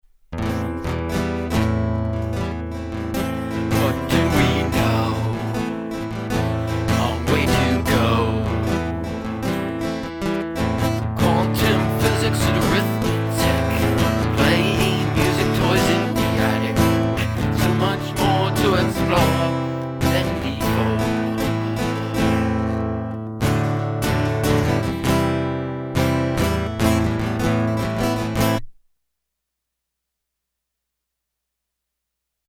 Chords: Am C Em Am / D Am / D C Am Instrumentation: Vocals, Guitar, Loop Pedal, Keyboards (Casio WK-500, Korg N364, MiniNova) An improvisational song written and recorded on-the-fly to a digital 1-track